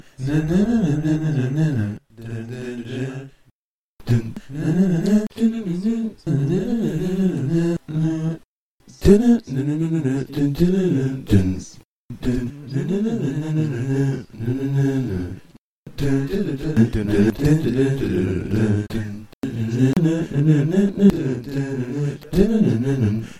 Eventually I realized that I needed a much more clear and simple guide track to follow with the guitar, so I recorded my self humming along with the various fragments of the story.
After this guide track was layed out, the guitar tracked followed very quickly, taking a little over an hour to figure out each segment on the guitar and through the same method, loop and punch in several takes of that segment
The Story , The Guide Track
and The Guitar